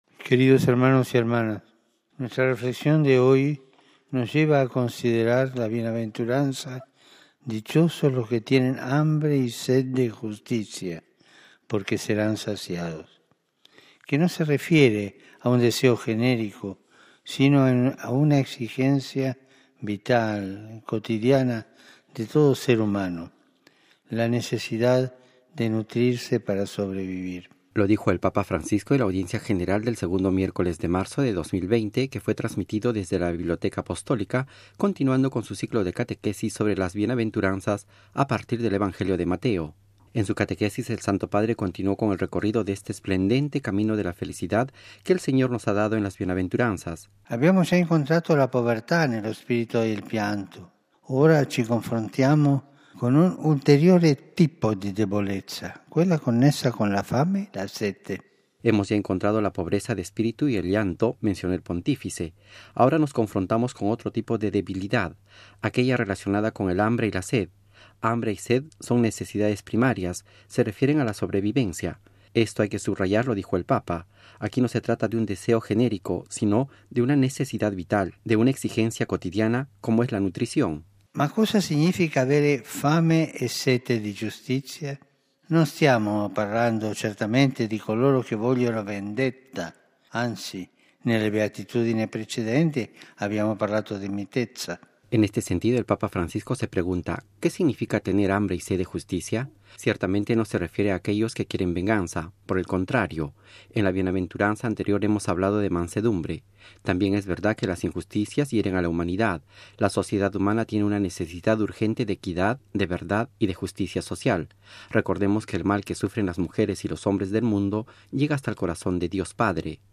La cuarta Bienaventuranza tomada del capítulo 5, versículo 6, del Evangelio de Mateo, es el tema de la catequesis del Santo Padre en la Audiencia General del miércoles 11 de marzo de 2020 y que fue transmitida desde la Biblioteca Apostólica.
Audio | Catequesis del Papa Francisco